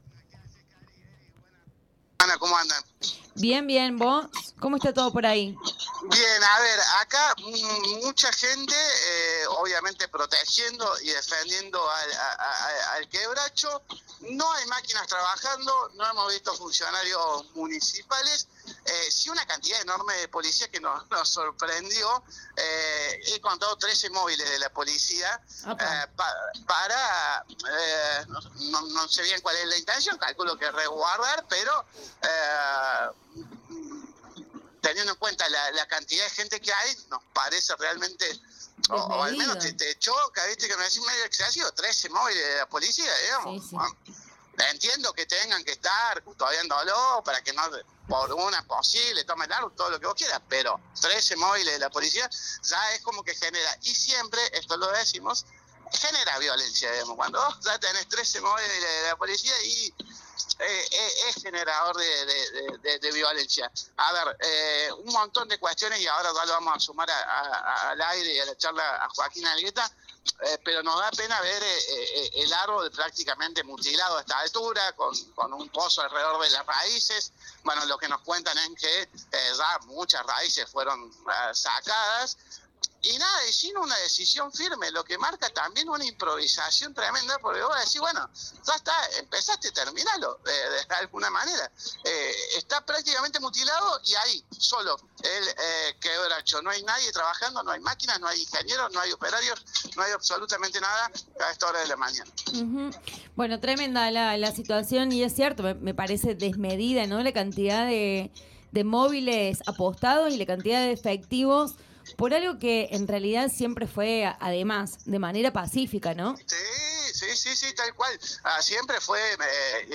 De pie Durante la entrevista